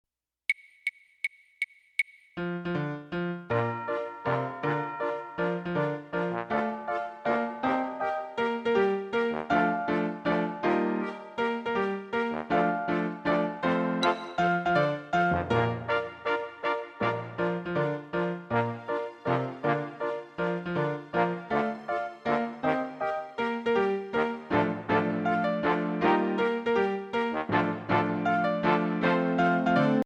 Voicing: Piano w/ Audio